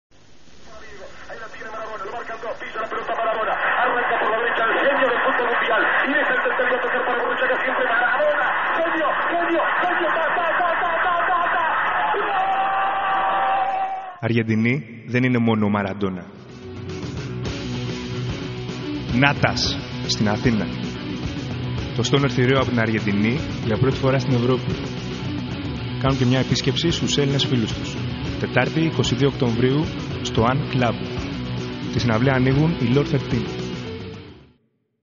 Radio Spot // Pictures